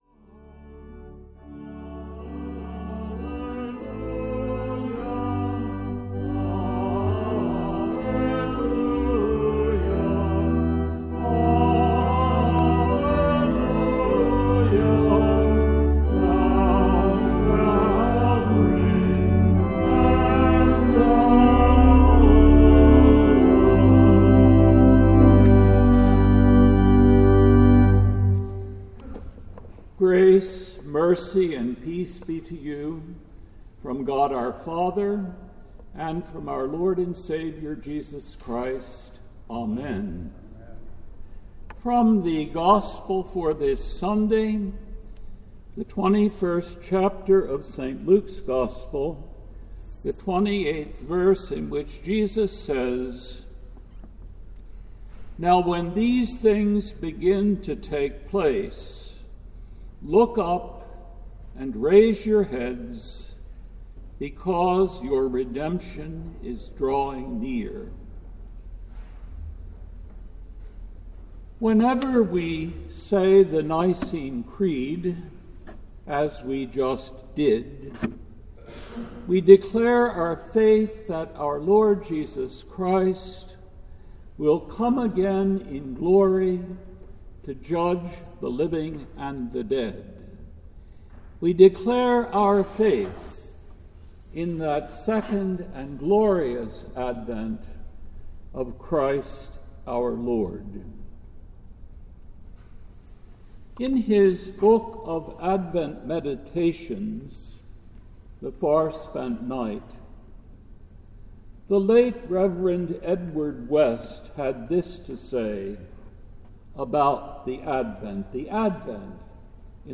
The Second Sunday in Advent December 6, 2020 AD